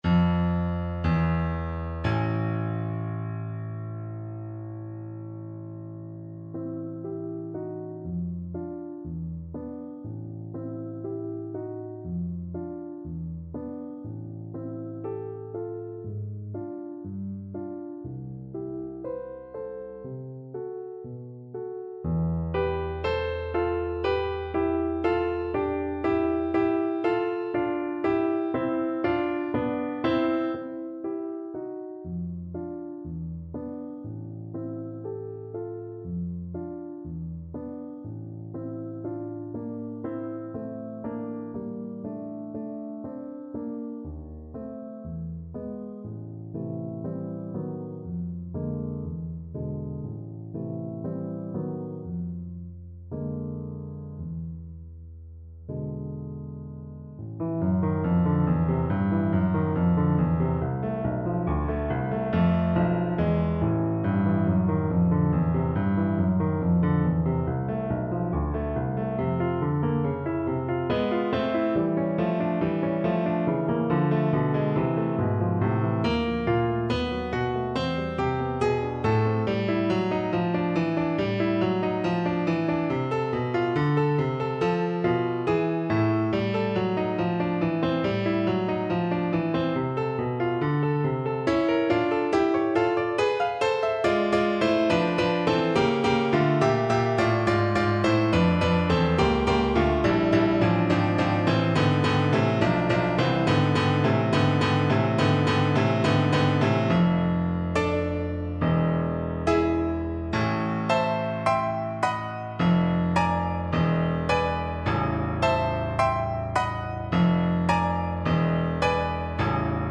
Double Bass
A minor (Sounding Pitch) (View more A minor Music for Double Bass )
Lento
4/4 (View more 4/4 Music)
E2-G4
Classical (View more Classical Double Bass Music)